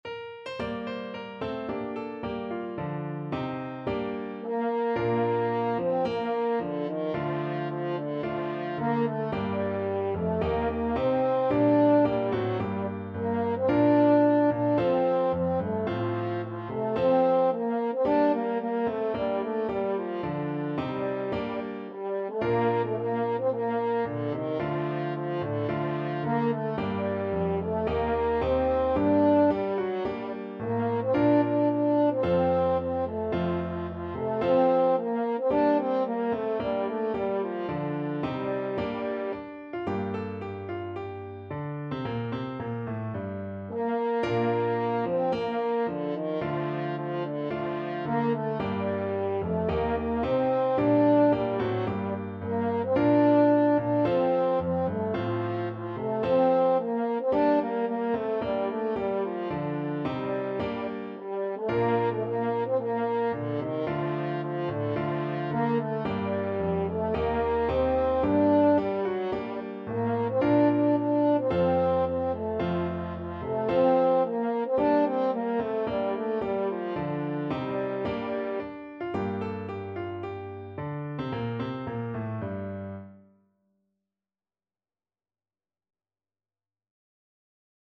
4/4 (View more 4/4 Music)
~ = 110 Allegro (View more music marked Allegro)